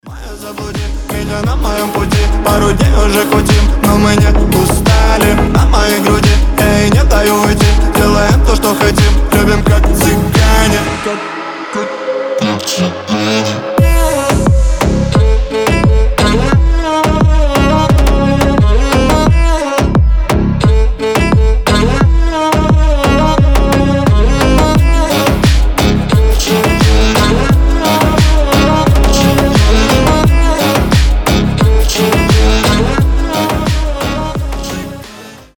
• Качество: 320, Stereo
ритмичные
deep house
мощные басы
скрипка
восточные
качающие
G-House